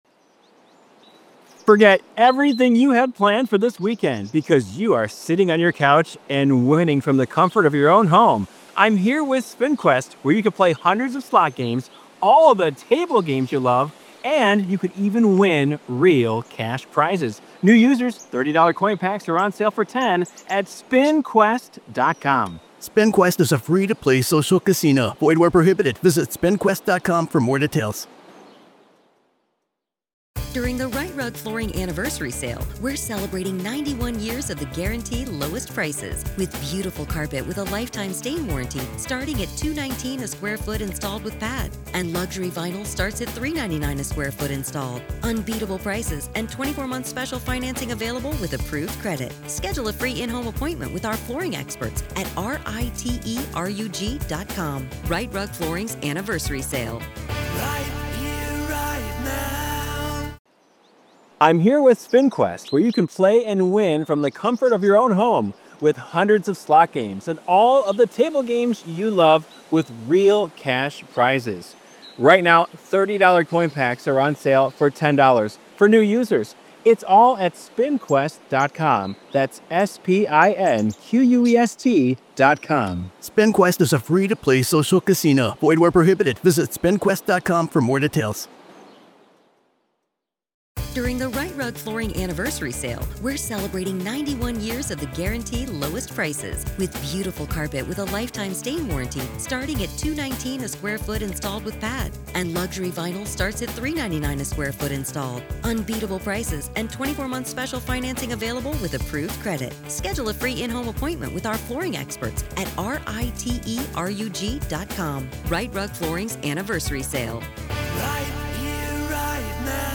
The conversation provided insights into the legal intricacies and potential outcomes of the ongoing trial, underscoring the importance of proper legal procedures and the challenges faced by both the defense and prosecution.